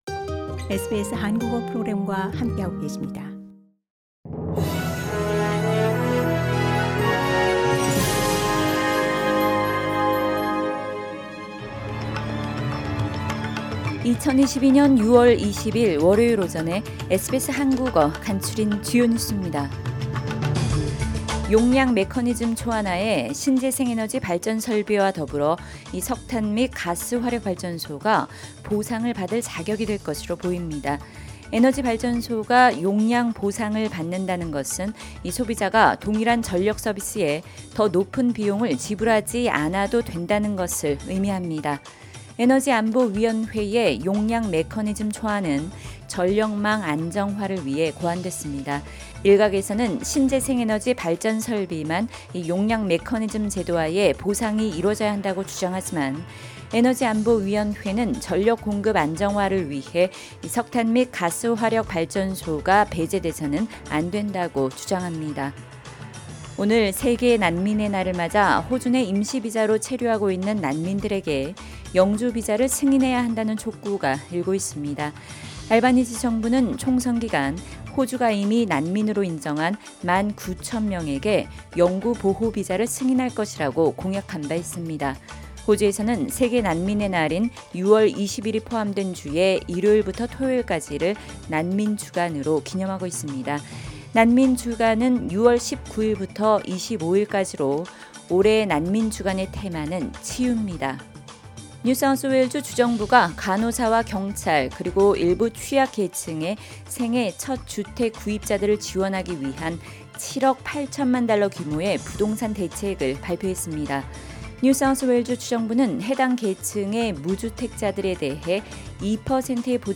2022년 6월 20일 월요일 아침 SBS 한국어 간추린 주요 뉴스입니다.